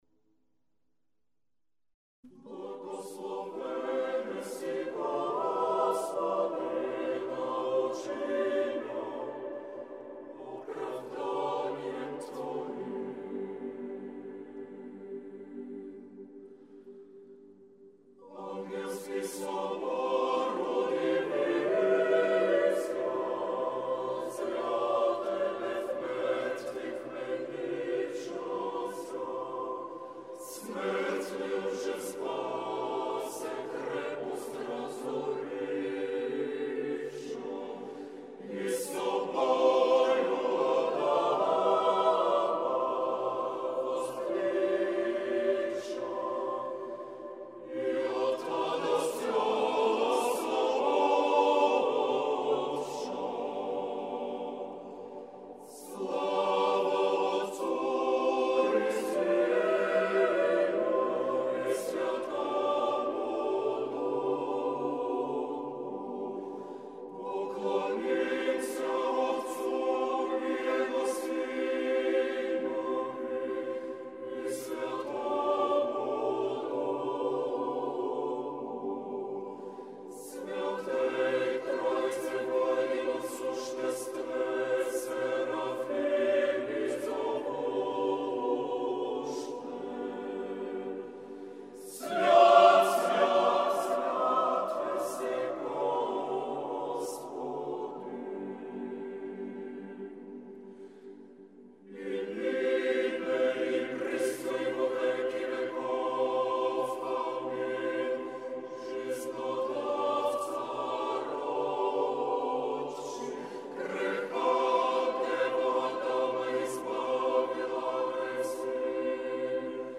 Православни песнопения